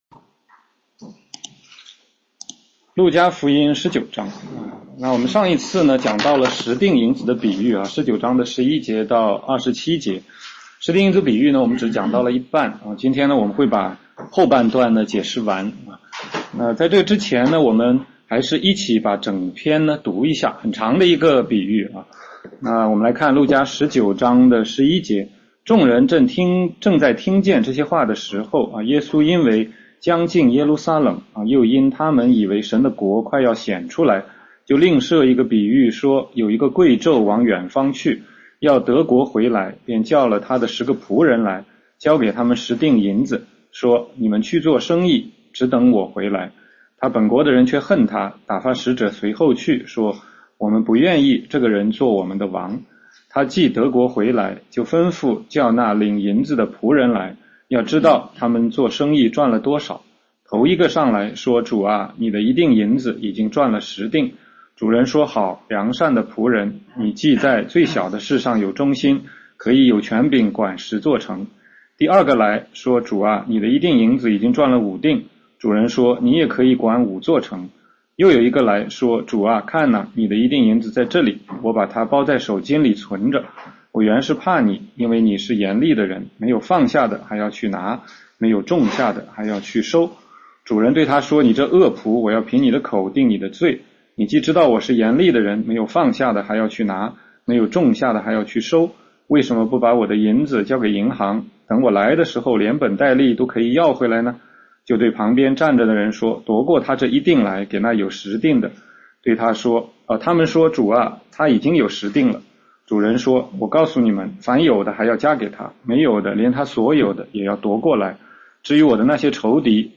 16街讲道录音 - 全中文查经